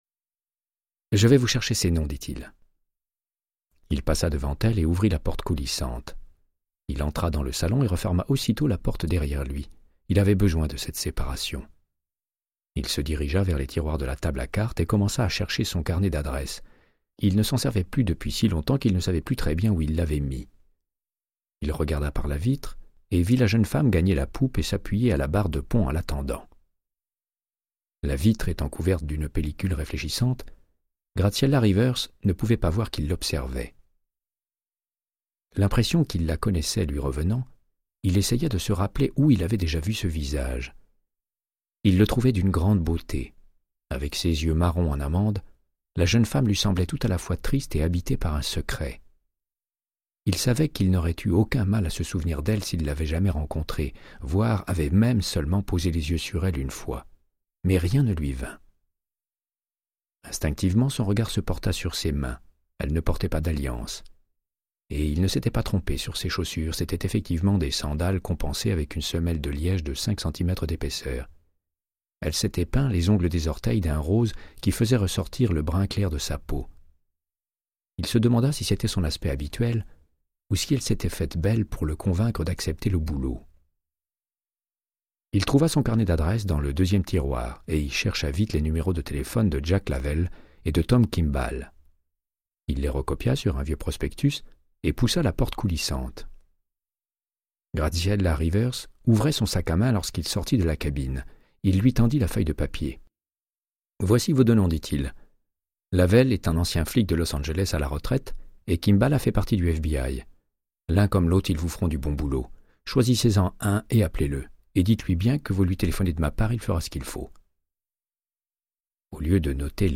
Audiobook = Créance de sang, de Michael Connellly - 03